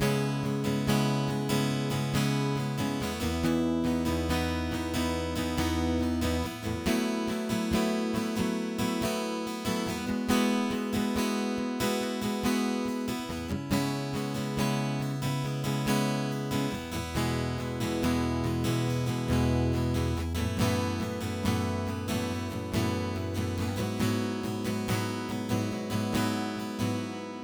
Guitar_Innocent_70bpm_C#m
Guitar_Innocent_70bpm_Cm.wav